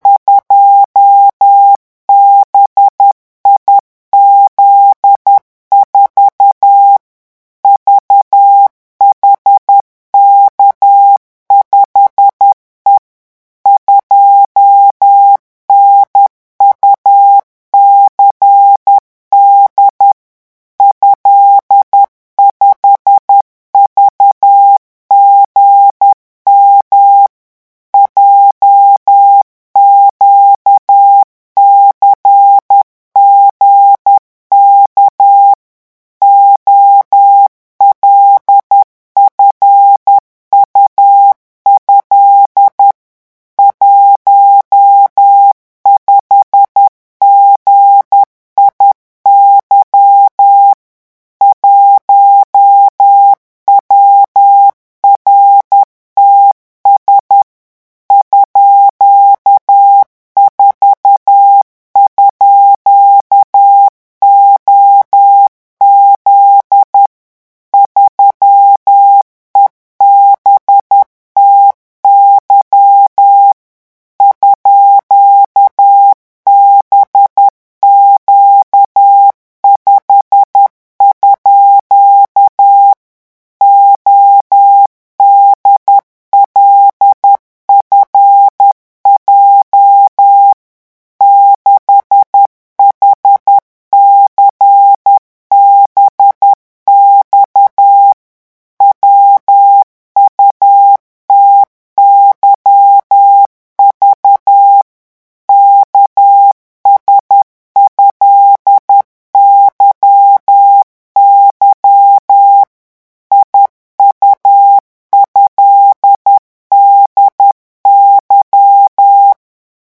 CODE MORSE-REVISION 10
Vitesse 10 mots minutes :
revision10-vitesse_10_mots.mp3